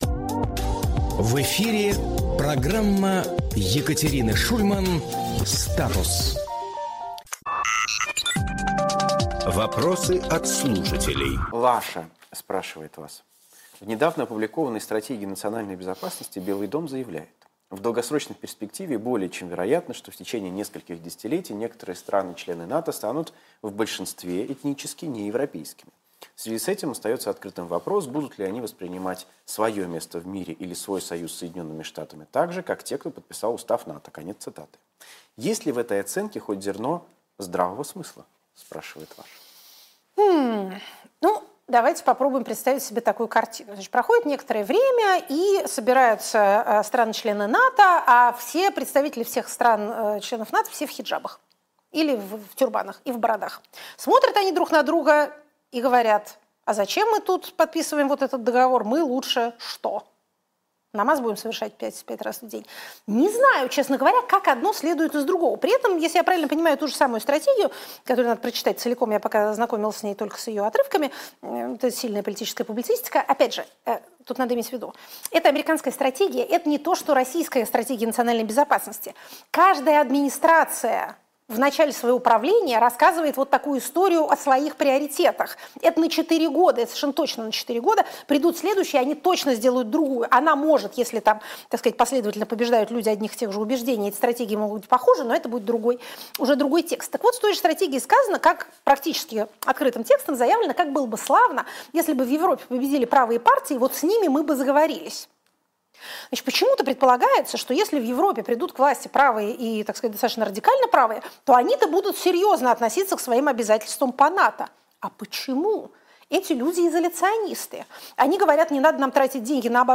Фрагмент эфира от 09.12.25